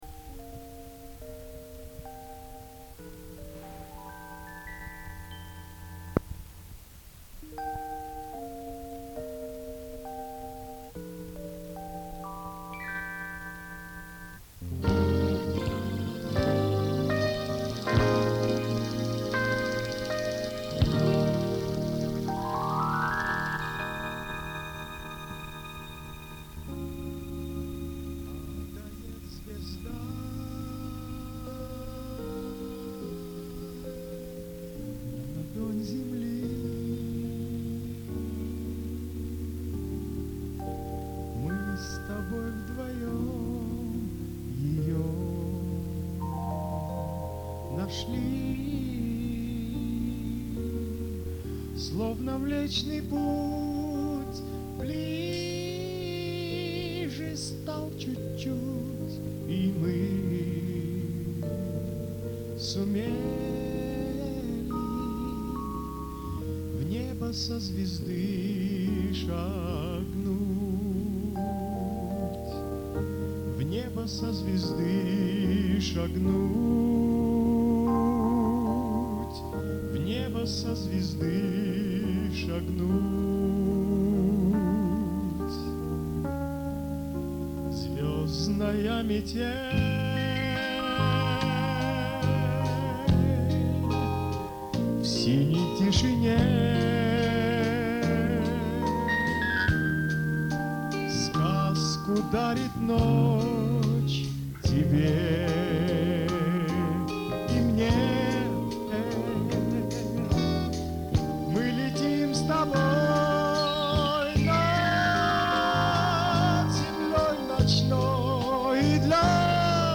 написанная в джаз-роке.